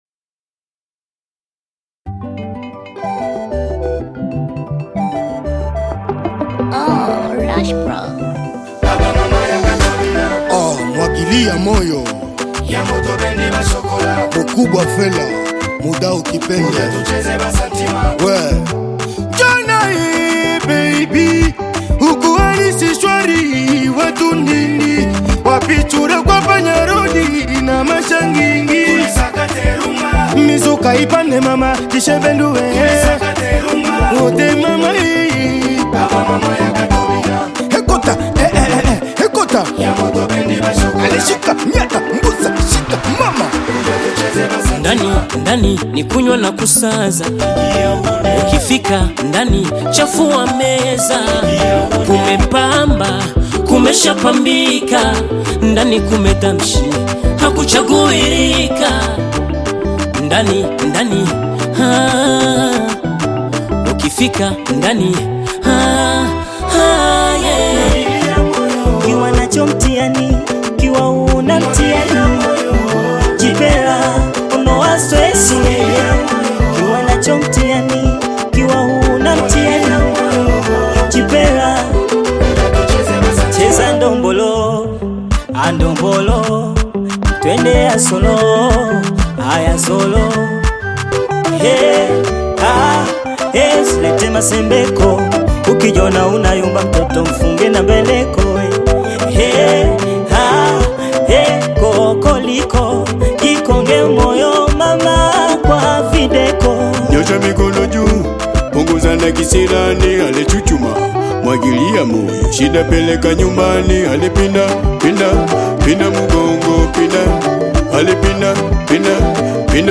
Bongo Flava song
Bongo Flava You may also like